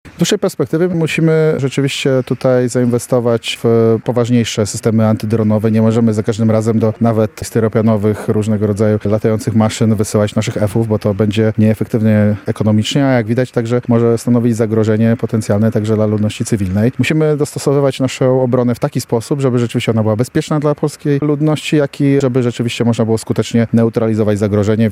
O sytuacji związanej z bezpieczeństwem w kraju mówił w Białej Podlaskiej poseł Konfederacji Witold Tumanowicz.